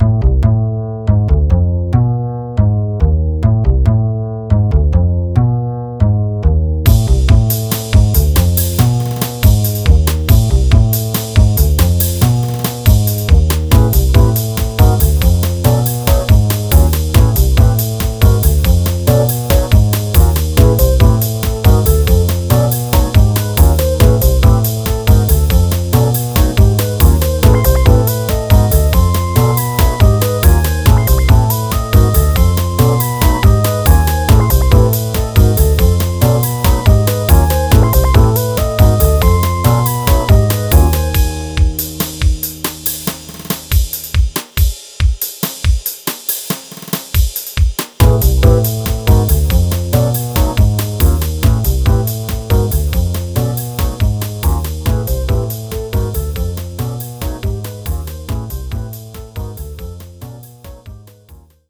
Genre Drum & Bass
a liitle too "bone-dry"